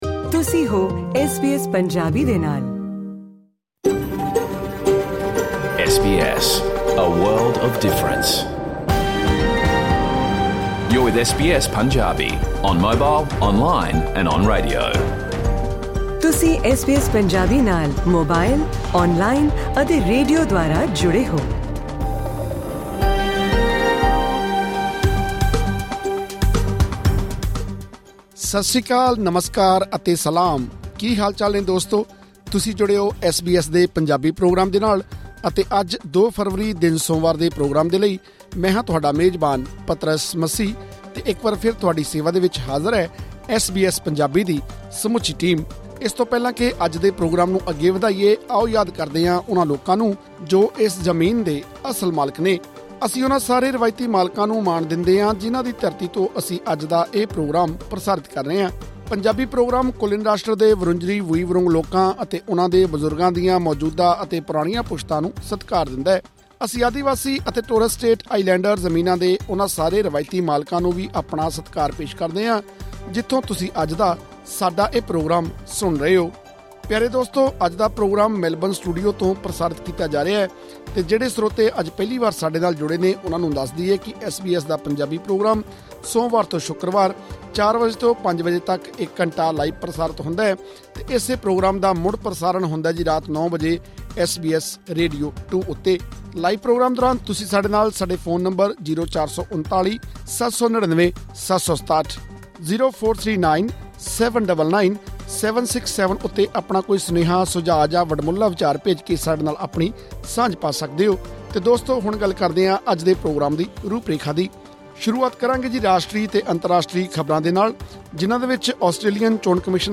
ਐਸ ਬੀ ਐਸ ਪੰਜਾਬੀ ਦਾ ਰੇਡੀਓ ਪ੍ਰੋਗਰਾਮ ਸੋਮਵਾਰ ਤੋਂ ਸ਼ੁੱਕਰਵਾਰ ਸ਼ਾਮ 4 ਵਜੇ ਤੋਂ 5 ਵਜੇ ਤੱਕ ਲਾਈਵ ਪ੍ਰਸਾਰਿਤ ਹੁੰਦਾ ਹੈ।